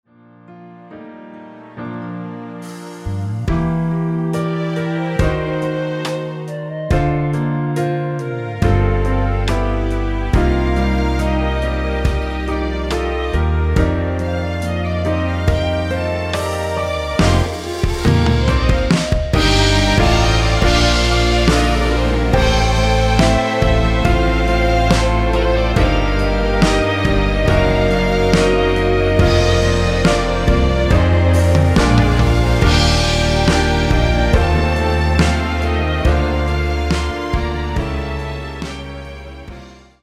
원키 멜로디 포함된(1절앞 + 후렴)으로 진행되는 MR입니다.
Bb
노래방에서 노래를 부르실때 노래 부분에 가이드 멜로디가 따라 나와서
앞부분30초, 뒷부분30초씩 편집해서 올려 드리고 있습니다.